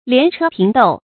連車平斗 注音： ㄌㄧㄢˊ ㄔㄜ ㄆㄧㄥˊ ㄉㄡˇ 讀音讀法： 意思解釋： 形容冗員很多 出處典故： 唐 張鷟《朝野僉載》第四卷：「補闕連車載，拾遺平斗量。」